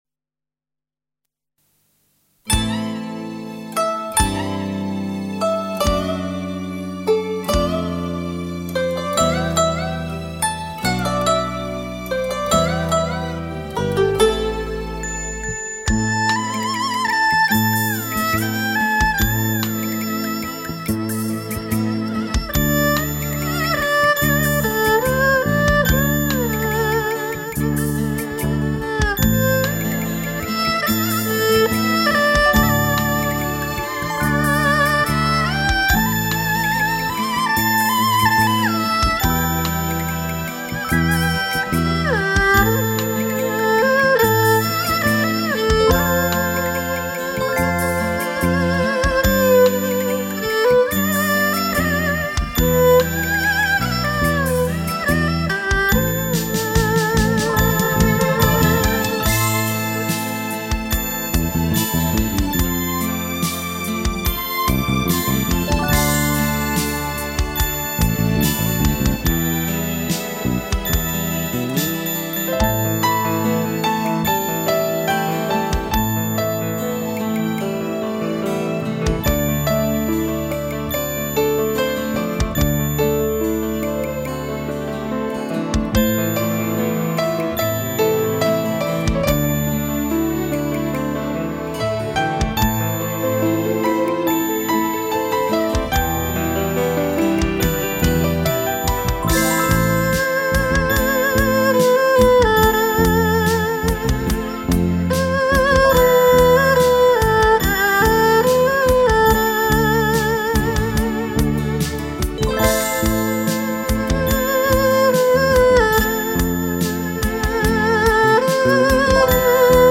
有轻松动听、脍炙人口的民歌精选、有旋律优美，婉转动人的柔情乐曲、有悠扬缠绵、回味难忘的影视插曲
一段乐曲足已把人引到一个世外桃源,尽享民族风情,如乘风驾云赏月,沁人心脾.